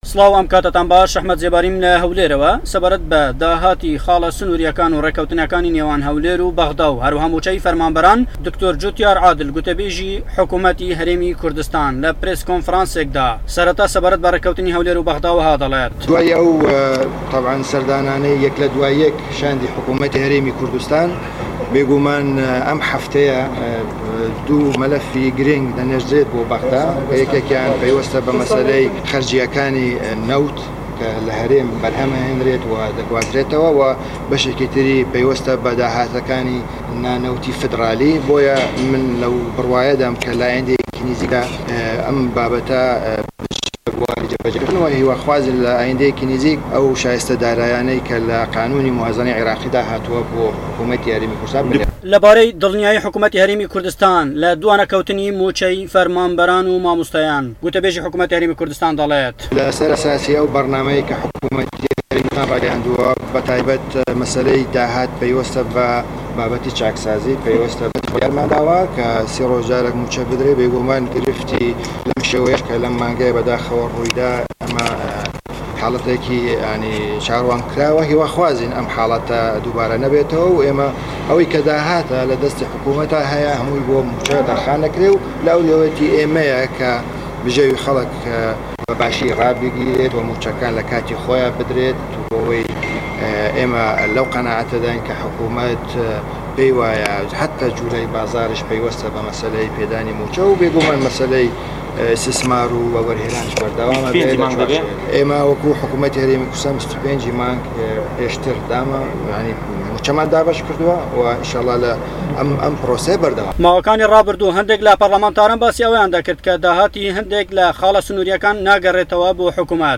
لە کۆنفرانسێکی ڕۆژنامەوانیدا